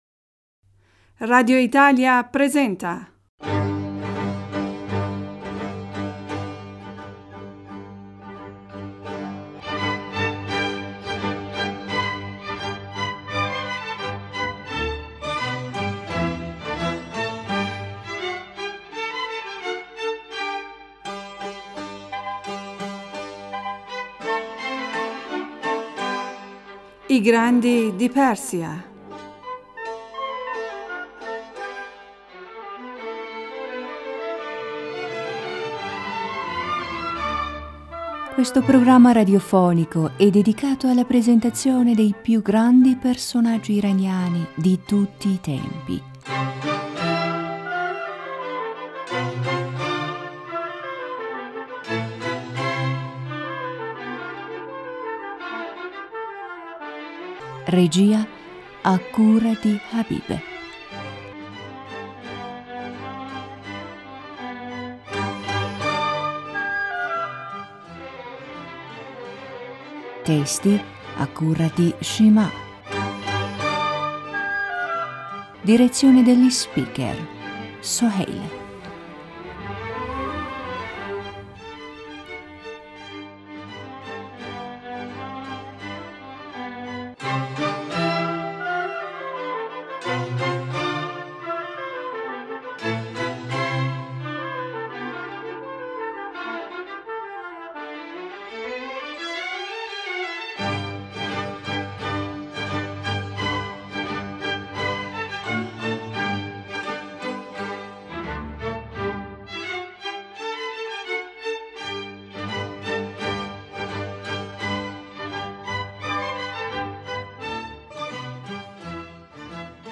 Benvenuti ad un’altra puntata della rappresentazione radiofonica“Grandi di Persia”, dedicata alla vita dei grandi personaggi scientifici e culturali dell’Iran.